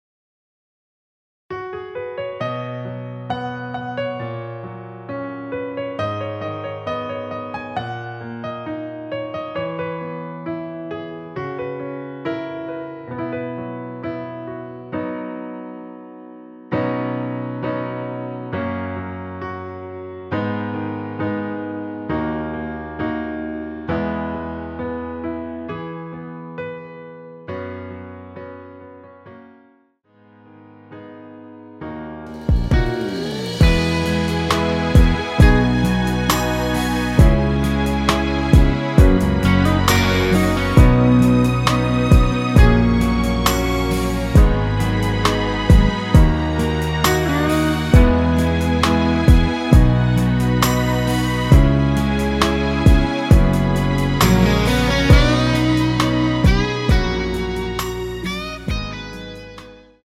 엔딩이 페이드 아웃이라 노래 하시기 좋게 엔딩을 만들어 놓았습니다.
원키에(+1)올린 MR입니다.
앞부분30초, 뒷부분30초씩 편집해서 올려 드리고 있습니다.